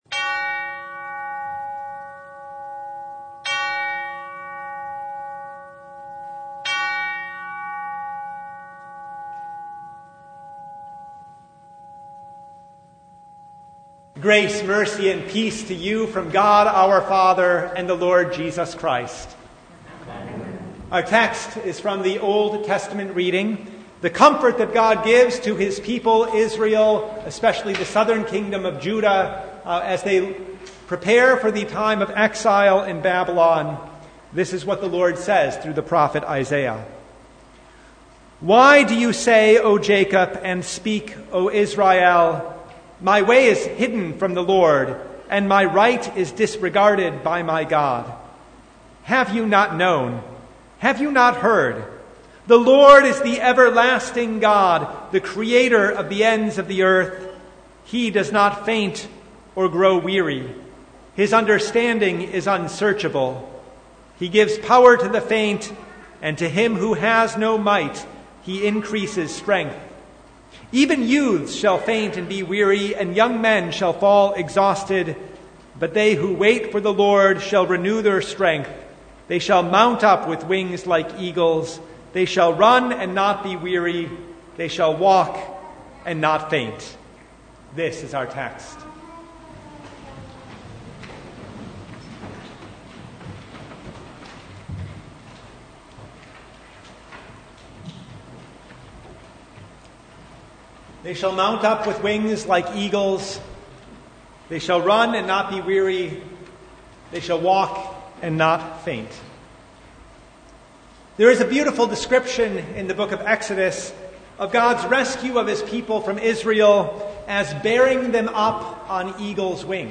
Isaiah 40:21-31 Service Type: Sunday The Lord is our strength and gives us comfort.